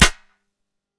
Whip Crack
A sharp bullwhip crack with approach whoosh and supersonic snap
whip-crack.mp3